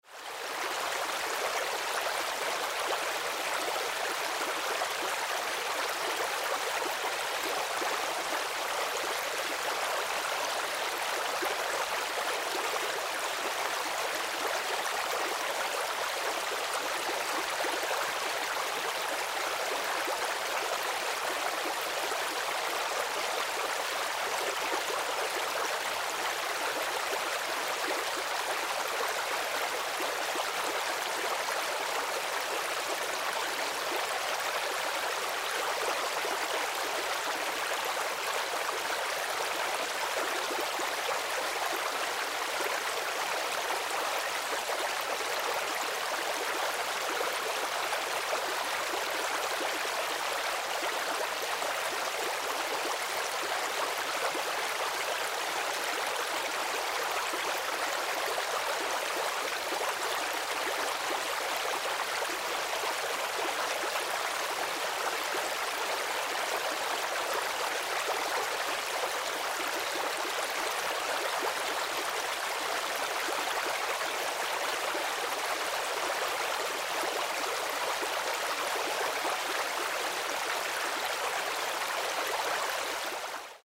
Звуки журчащего ручья
Погрузитесь в атмосферу природы с подборкой звуков журчащего ручья.